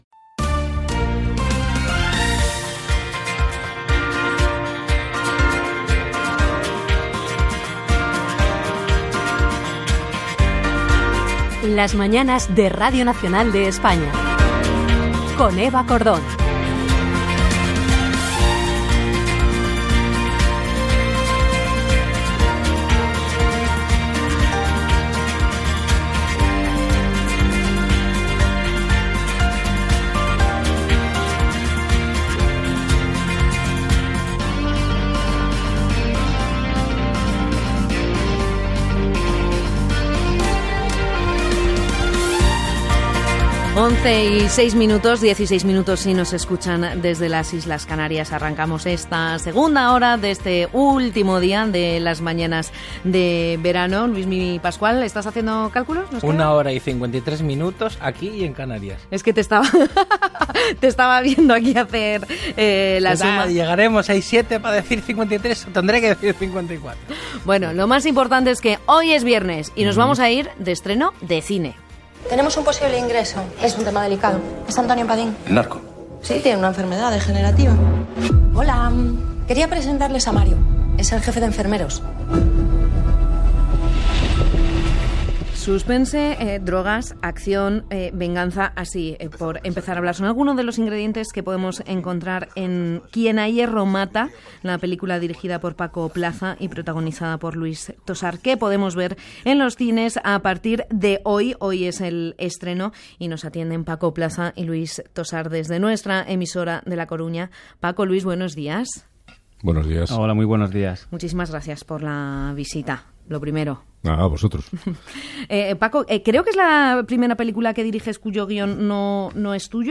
Careta del programa, hora, entrevista al director Paco Plaza i l'actor Luis Tosar amb motiu de l'estrena de la pel·lícula "Quien a hierro mata". Entrevista estiuenca al cantant El Chojín (Domingo Edjang Moreno).
Info-entreteniment